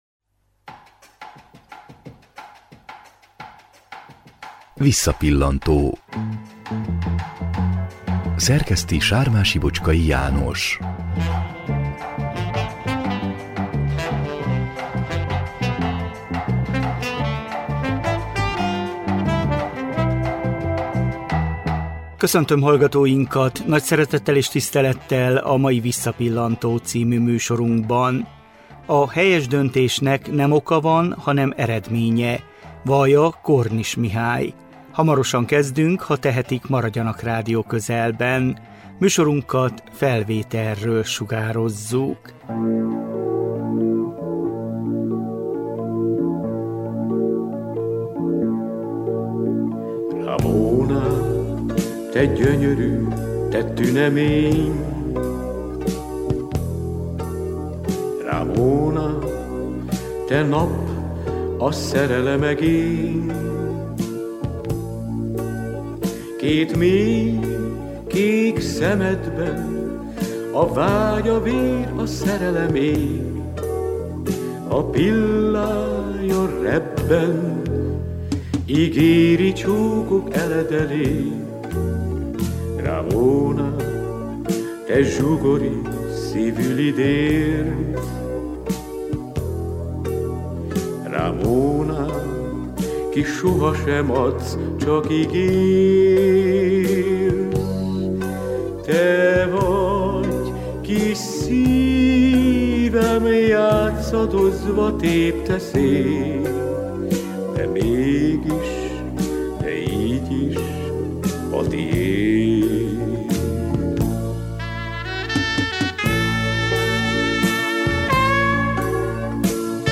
Zenés beszélgetésünk 2009 szeptemberében készült.